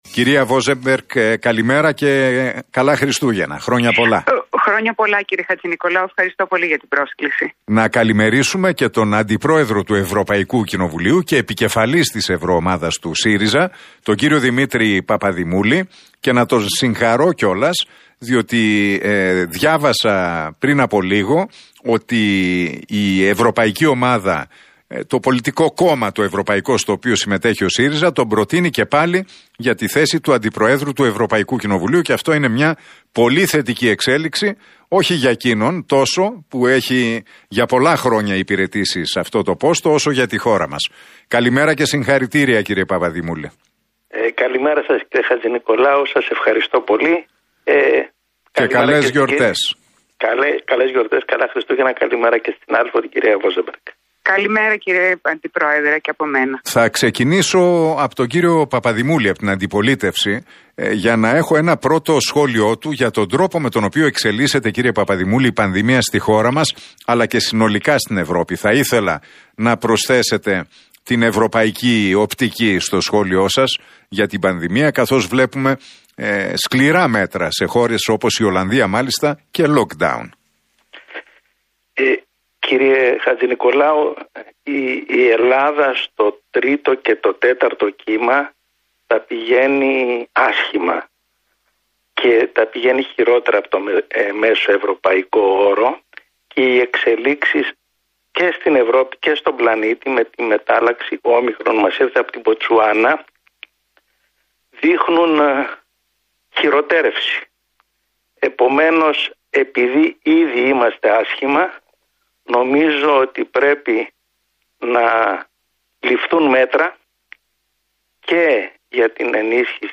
Τα ξίφη τους διασταύρωσαν στον αέρα του Realfm 97,8 σε ένα ραδιοφωνικό debate στην εκπομπή του Νίκου Χατζηνικολάου η Ελίζα Βόζεμπεργκ και ο Δημήτρης Παπαδημούλης.